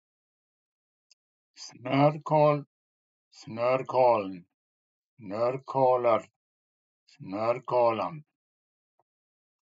Så här uttalar man ordet snôrkal